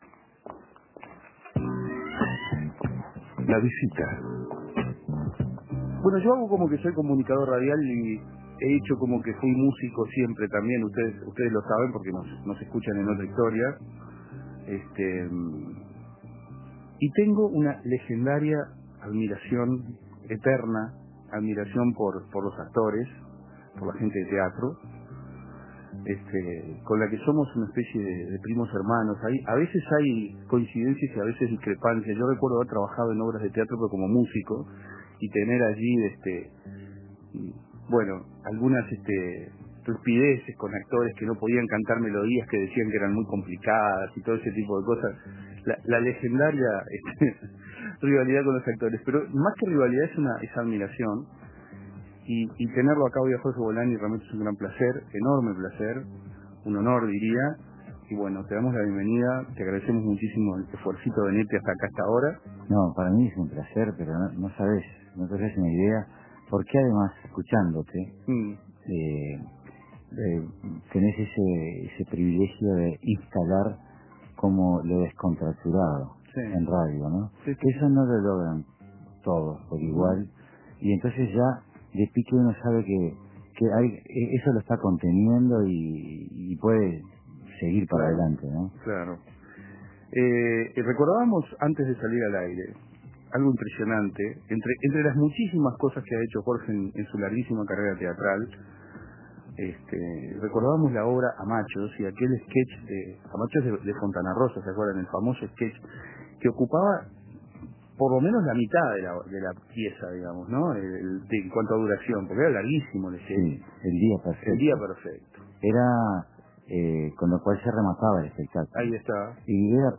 Entrevista al actor Jorge Bolani